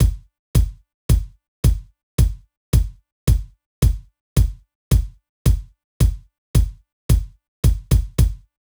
13 Kick.wav